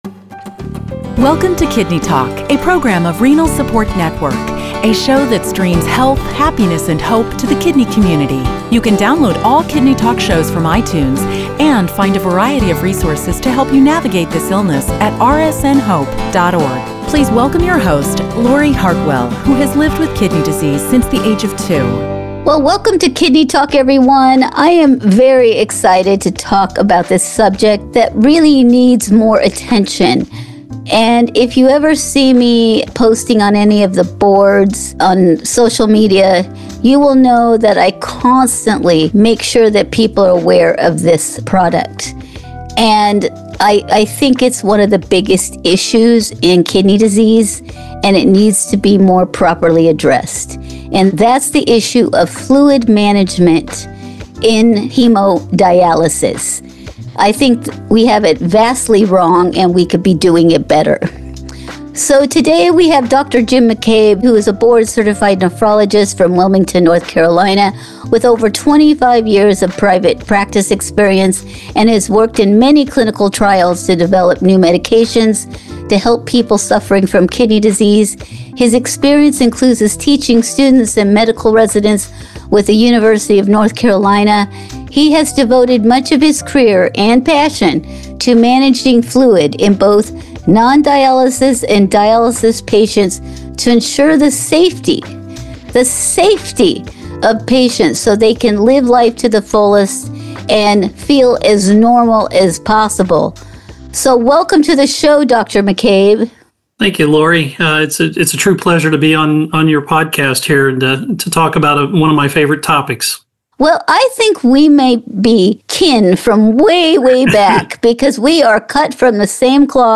In this two-part conversation